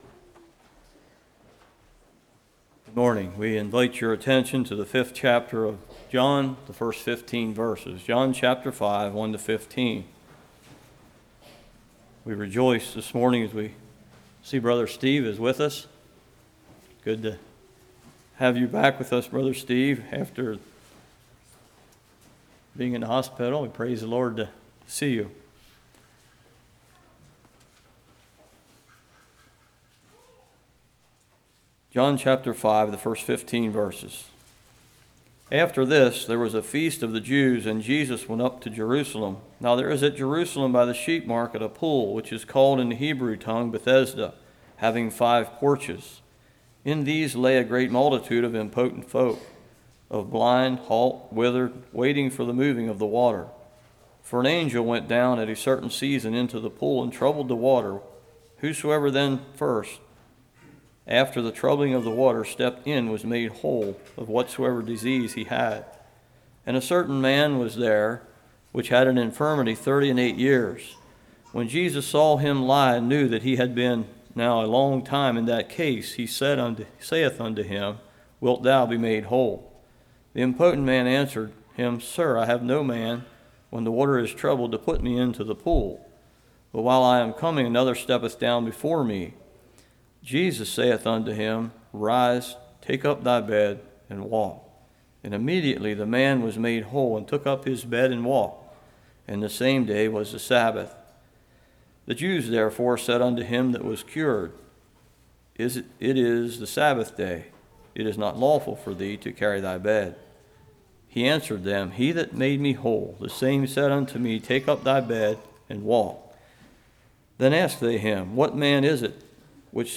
John 5:1-15 Service Type: Morning The Impotent Man Had Hope An Angel Troubles the Water The Law of God Can’t Save Us Wilt Thou Be Made Whole?